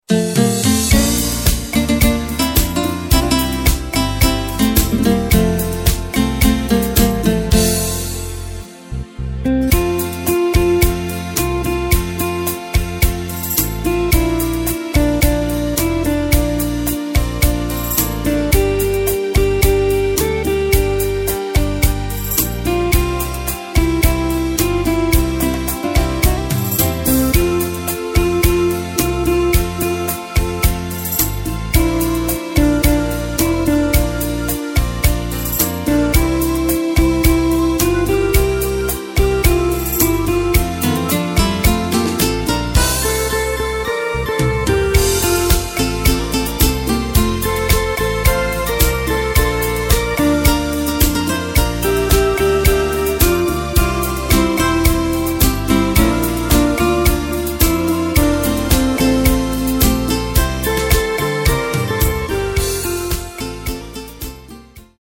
Takt:          4/4
Tempo:         109.00
Tonart:            F
Schweizer Schlager aus dem Jahr 2014!